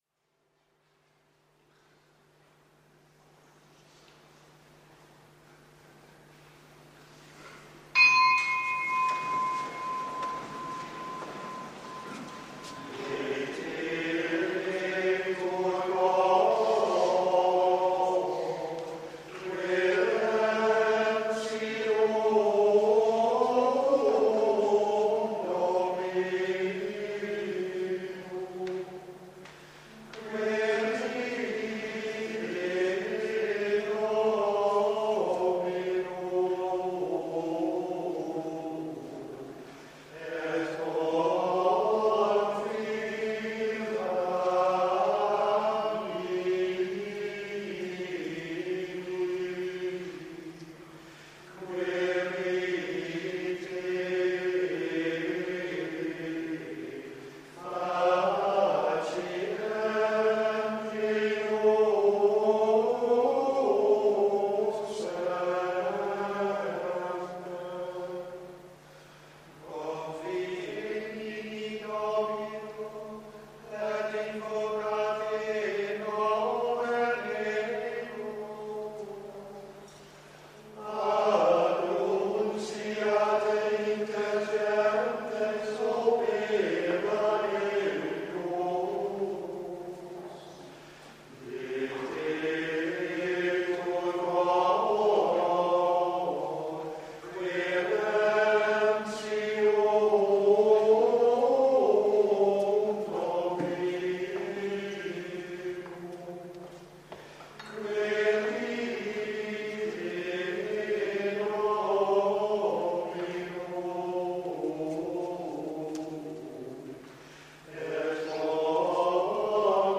Preek 4e zondag
Eucharistieviering in de parochie van de H. Augustinus, in de kerken van de H. Willibrord (Oegstgeest) en de H. Joannes de Doper (Katwijk), weekeinde van 28 en 29 januari 2017, om 19.00, 09.30 en 11.00 uur,